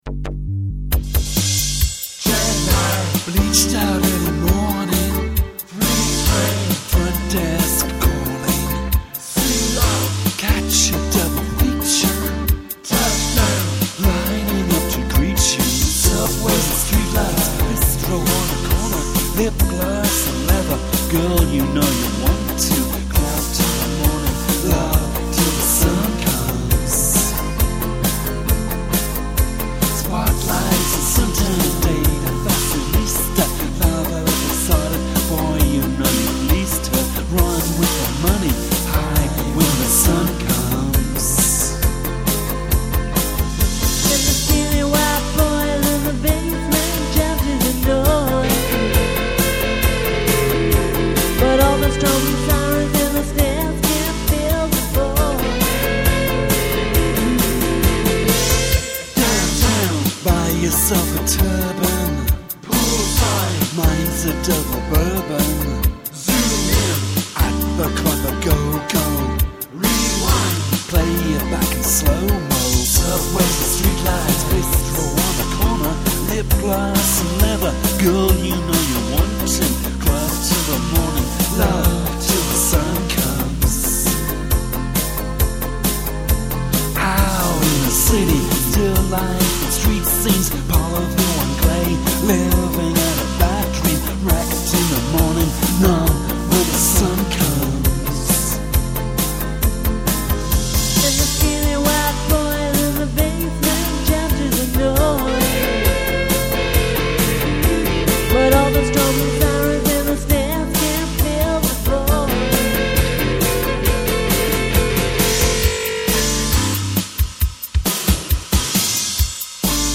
A live performance of the song '
Limelight Theatre, Aylesbury, UK